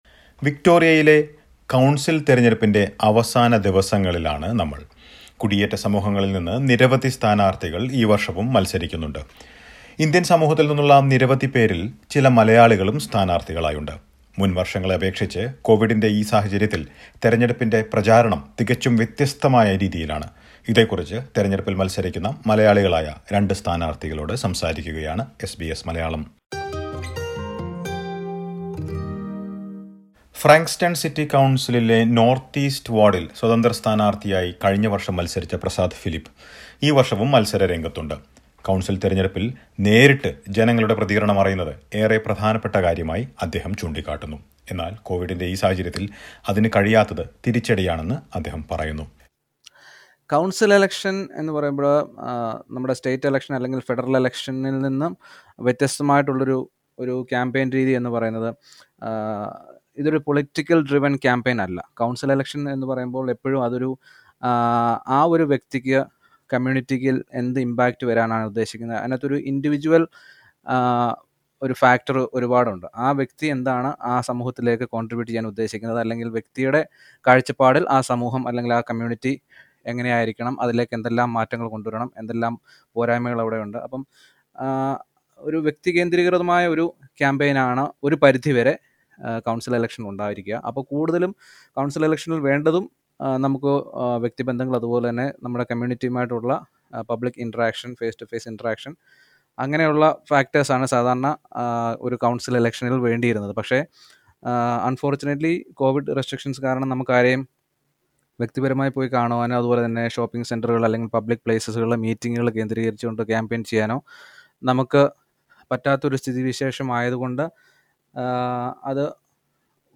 Victorian council election campaigns have almost completely gone online due to the coronavirus pandemic. Listen to a report.